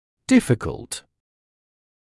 [‘dɪfɪkəlt][‘дификэлт]трудный, сложный